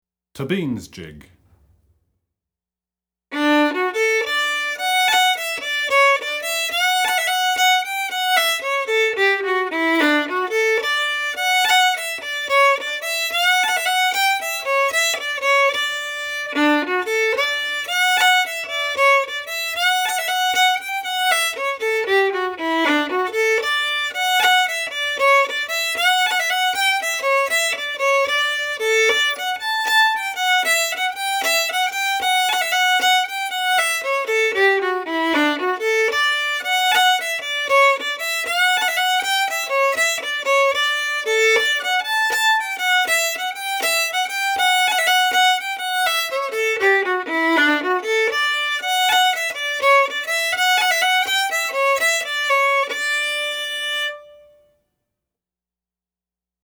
FIDDLE SOLO Fiddle Solo, Celtic/Irish, Jig
DIGITAL SHEET MUSIC - FIDDLE SOLO